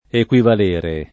equivalere [ ek U ival % re ] v.